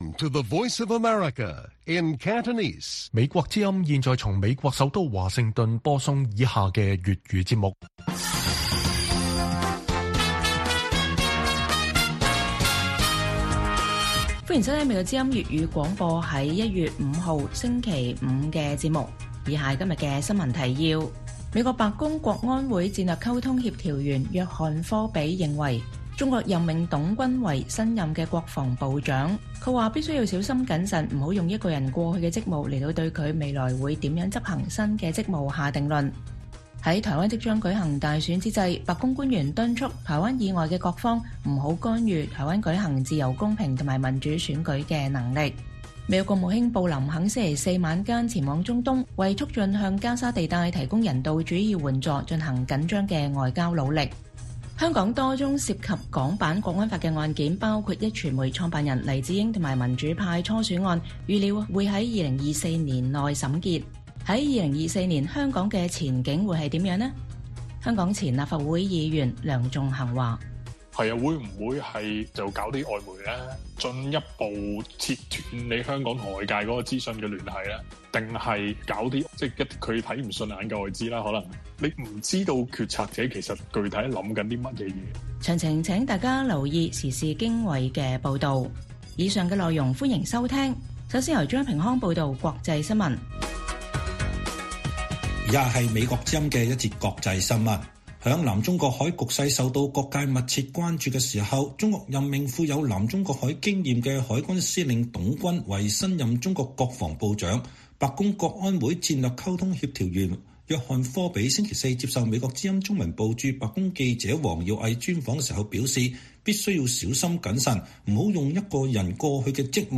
粵語新聞 晚上9-10點: 白宮將採取謹慎態度與中國新任國防部長董軍交往